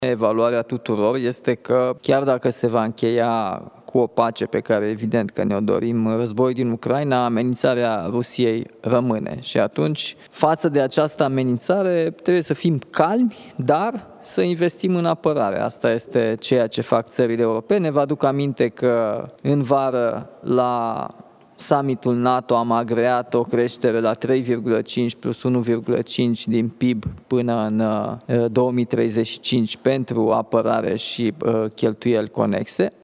Declarațiile au fost făcute în Finlanda, unde președintele Nicușor Dan participă la Summitul Statelor Uniunii Europene de pe Flancul de Est. Chiar și cu o pace în Ucraina, Rusia rămâne o amenințare, spune șeful statului.